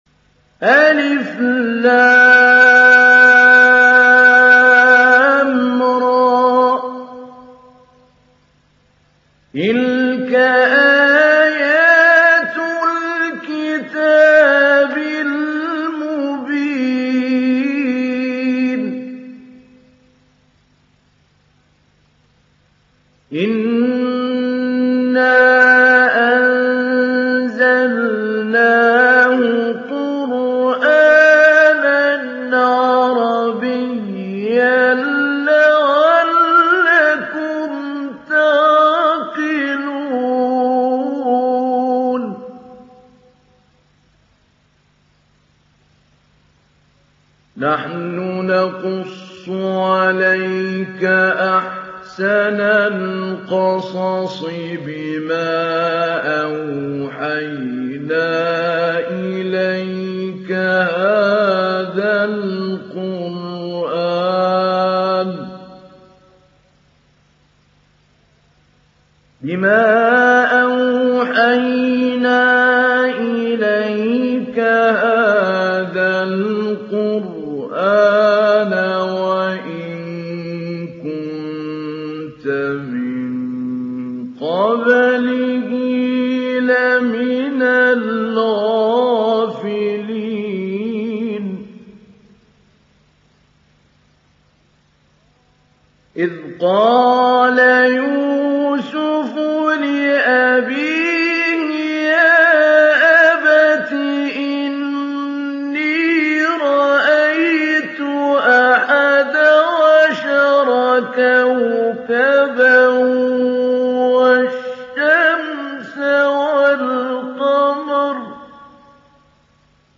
تحميل سورة يوسف mp3 بصوت محمود علي البنا مجود برواية حفص عن عاصم, تحميل استماع القرآن الكريم على الجوال mp3 كاملا بروابط مباشرة وسريعة
تحميل سورة يوسف محمود علي البنا مجود